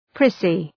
Προφορά
{‘prısı}